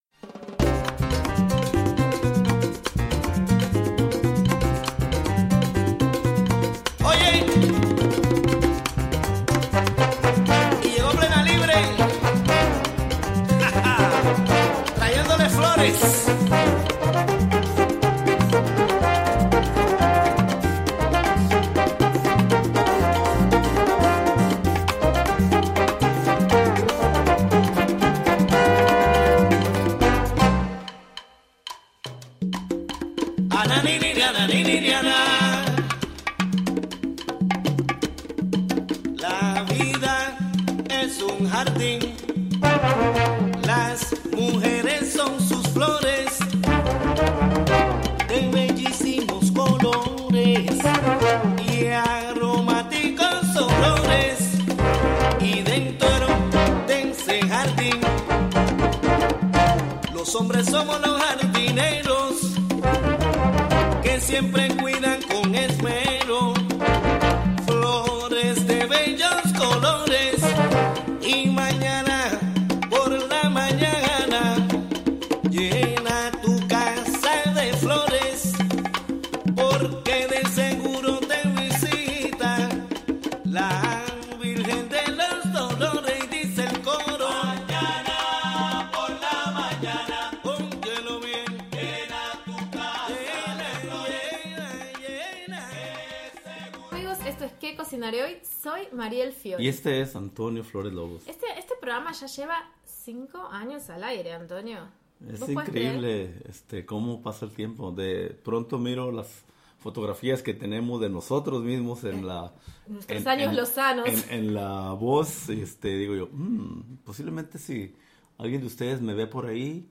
11am Un programa imperdible con noticias, entrevistas,...
This week in ¿Qué cocinaré hoy? we celebrate the fifth year of our radio show!